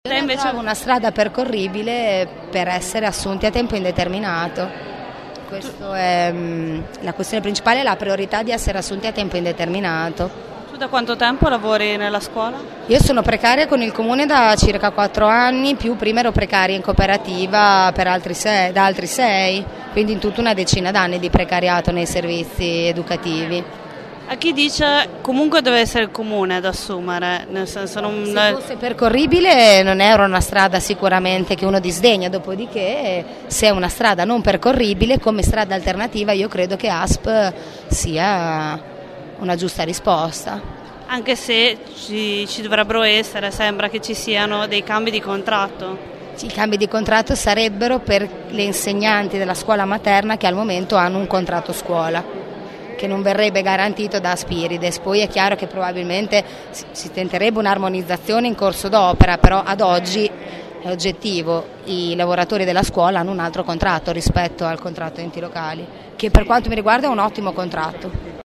Con il corpo coperto da dei lenzuoli bianchi hanno protestato silenziosamente per chiedere di essere assunti. Sono educatori e collaboratori precari dei nidi comunali assunti da Asp e il prossimo 30 giugno scadrà il loro contratto.